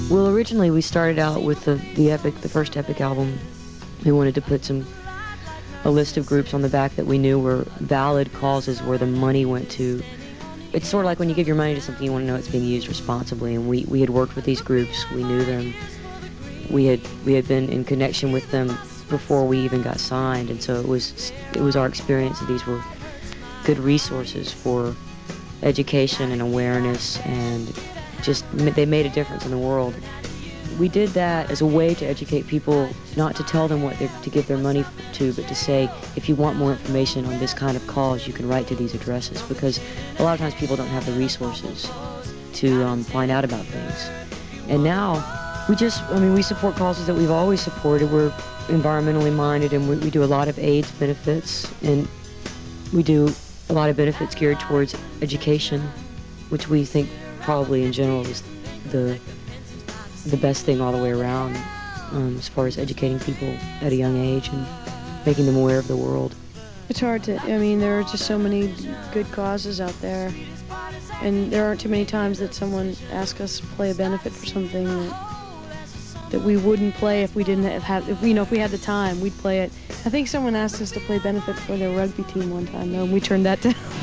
(acoustic duo show)
03. interview (1:24)